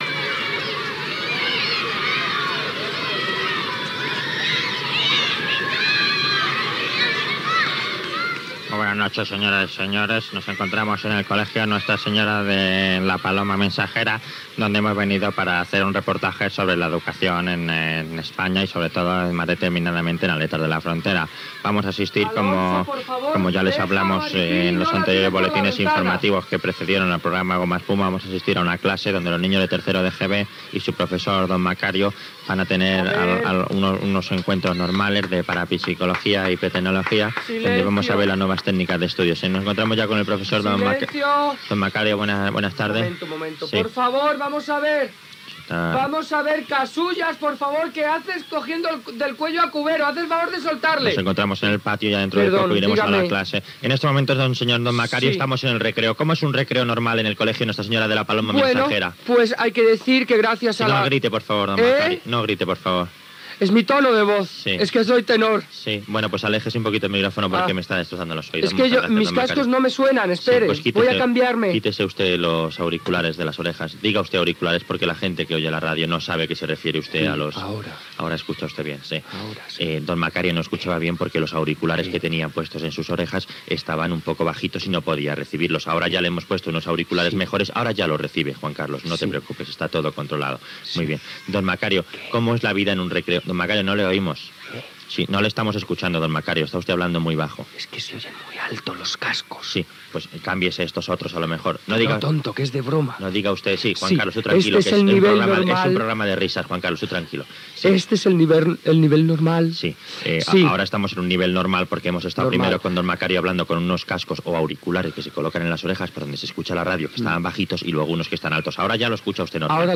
ad7600ccd1549c77d5a128253ac16dd2acf91a54.mp3 Títol Antena 3 Radio Emissora Antena 3 de Barcelona Cadena Antena 3 Radio Titularitat Privada estatal Nom programa Gomaespuma Descripció Entrevista a un alumne i el mestre don Macario al Colegio Nuestra Señora de la Paloma Mensajera con Patas d'Aletas de la Frontera.
Gènere radiofònic Entreteniment
Fragment de l'últim programa de "Gomaespuma" a Antena 3.